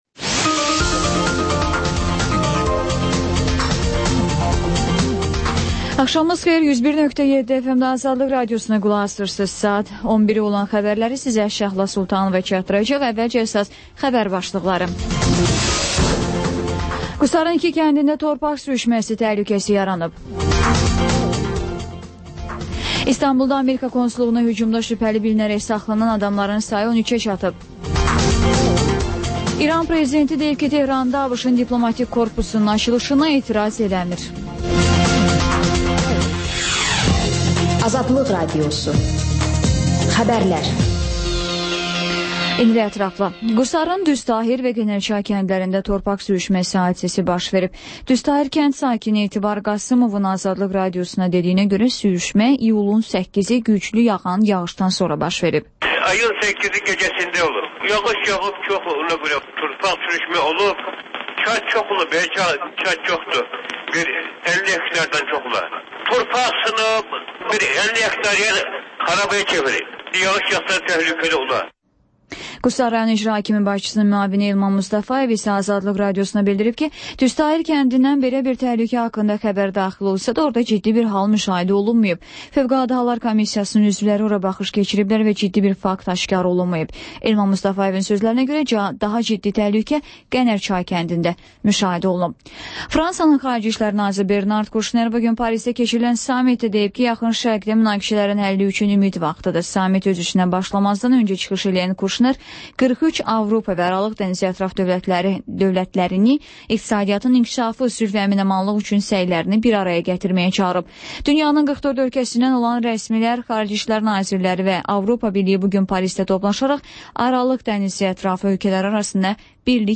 Xəbərlər, REP-TIME: Gənclərin musiqi verilişi